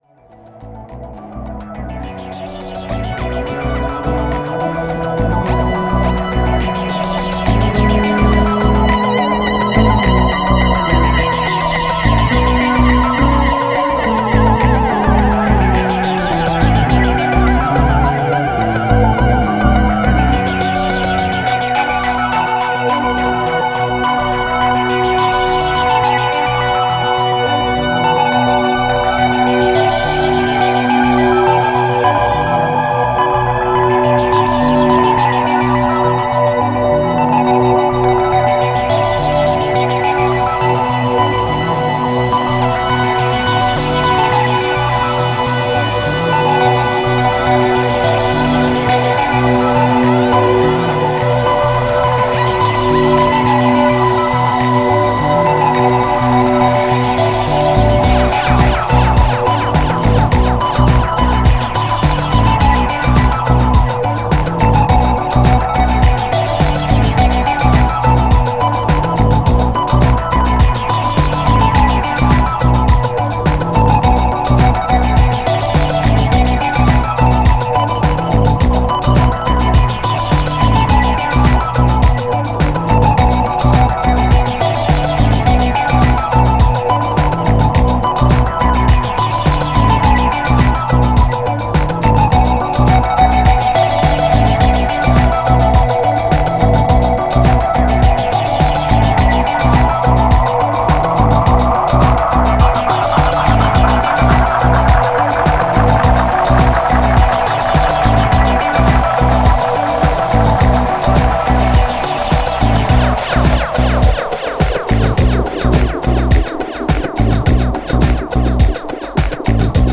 Italy’s coolest post rock, cosmic disco outfit
cosmic imaginary soundscapes, where Kraut rock fuses